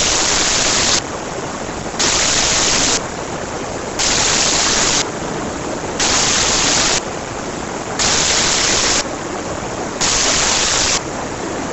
Here's an example of EQ run against your noise file. You'll hear the before and after every 1 second.
eq_example.wav